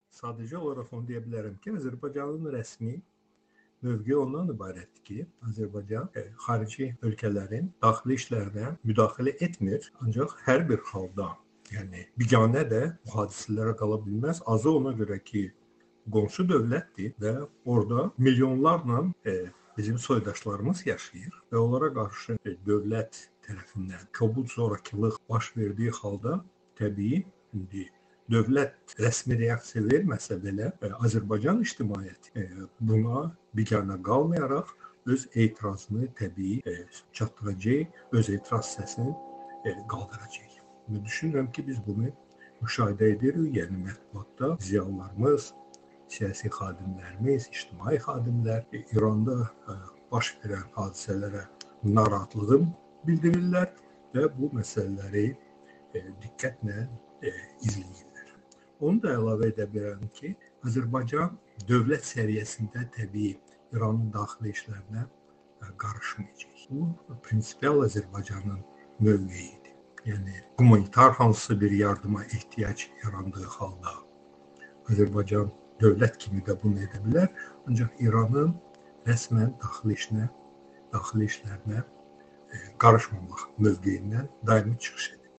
Bu sözləri Amerikanın Səsinə müsahibəsində Azərbaycan millət vəkili Rasim Musabəyov İrandakı etiraz hərəkatının Azərbaycana təsirlərini şərh edərkən deyib.